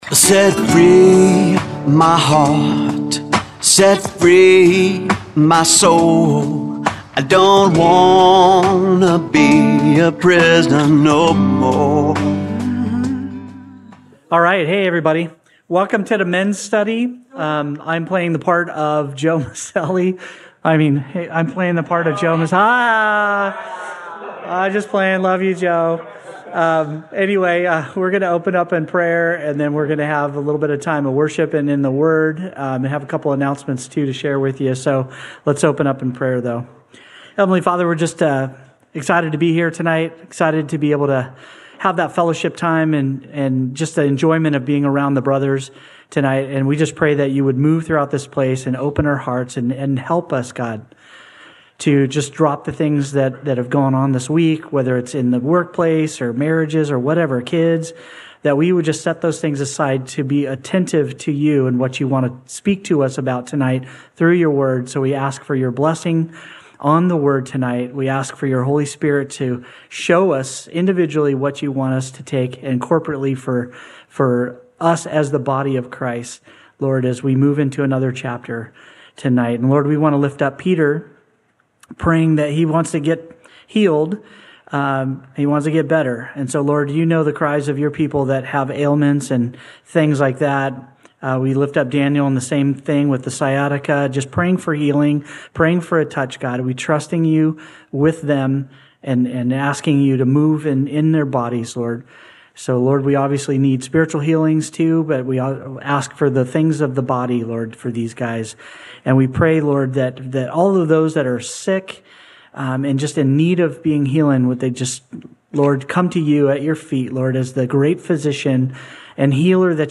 Men’s Study – Audio-only Sermon Archive